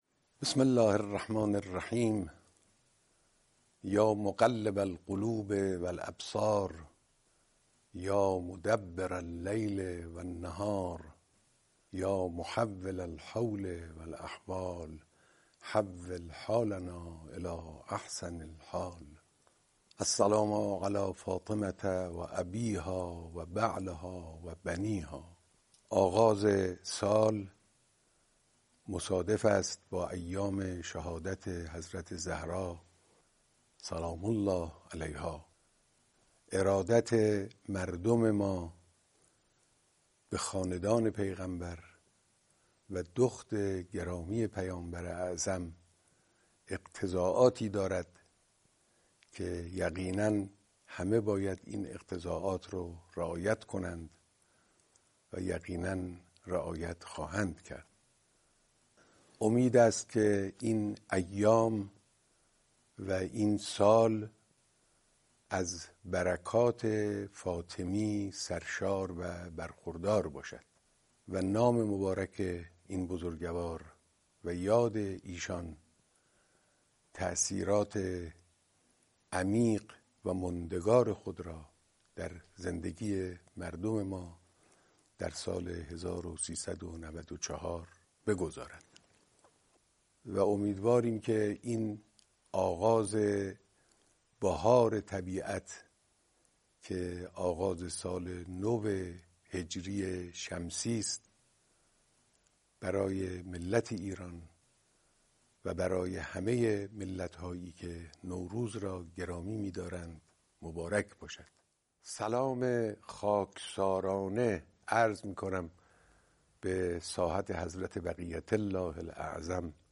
فایل صوتی پيام نوروزى امام خامنه ای به مناسبت آغاز سال ۱۳۹۲ کیفیت خوب با حجم 10.1مگابایت